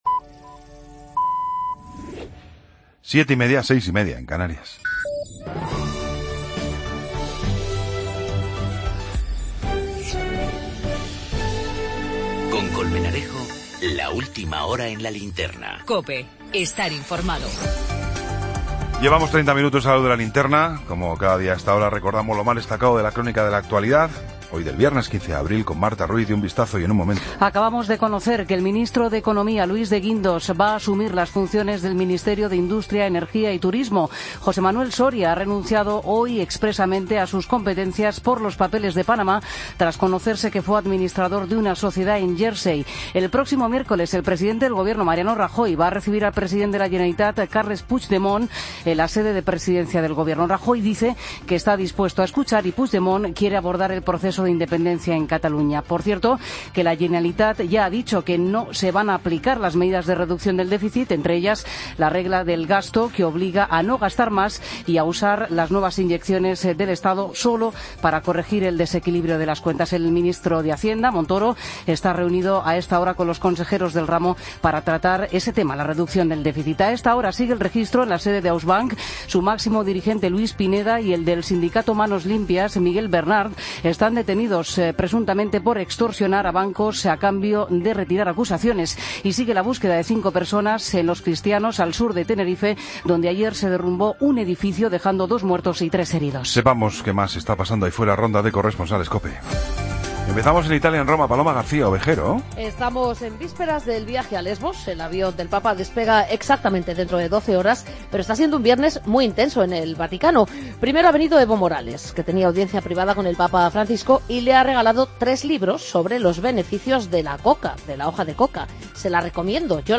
Ronda de corresponsales.